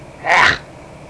Ack Sound Effect
ack.mp3